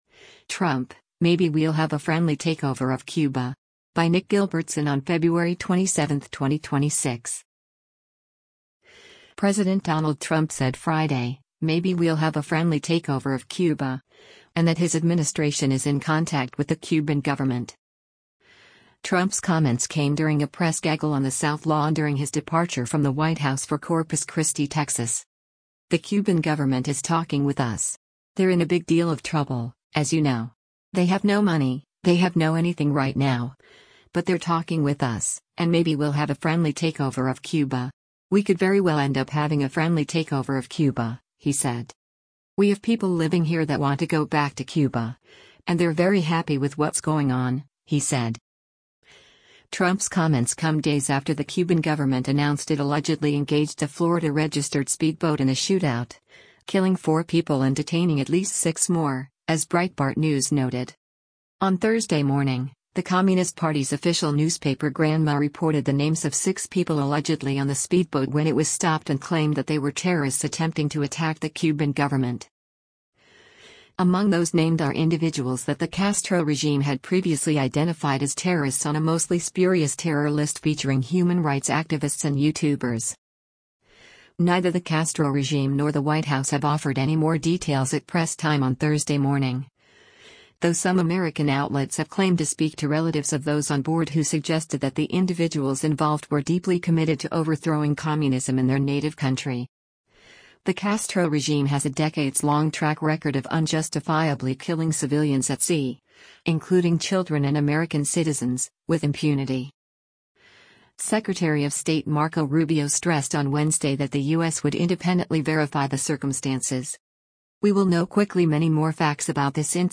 Trump’s comments came during a press gaggle on the South Lawn during his departure from the White House for Corpus Christi, Texas.